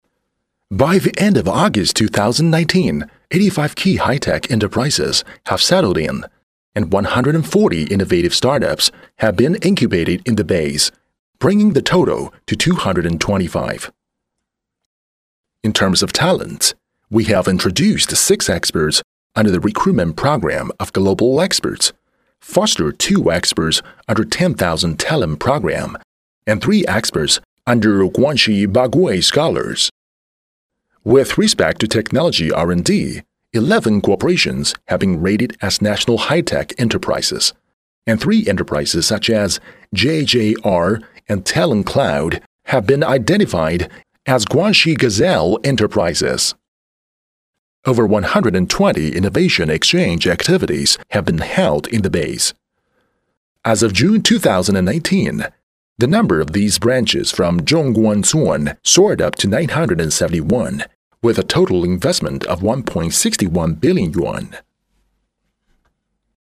国人英语男 | 声腾文化传媒
【英语】洋气质感Aw605.mp3